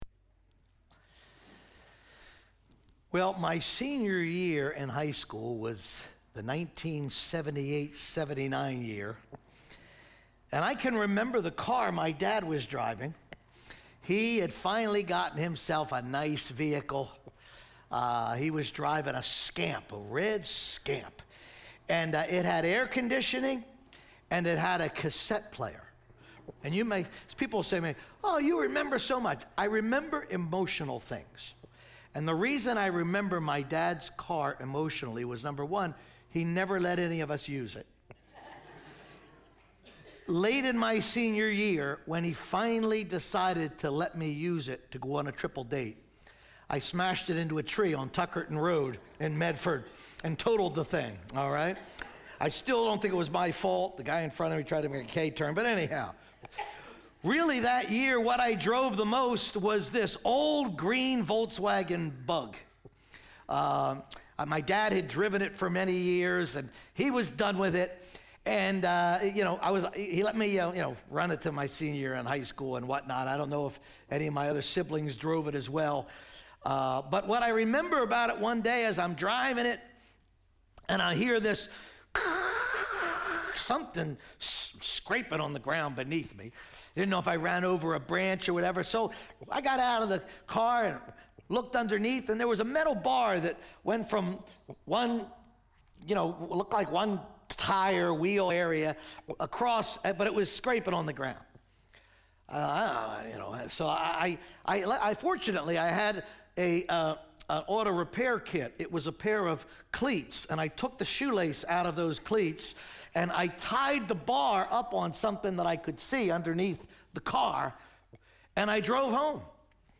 teaches from Luke 2:19.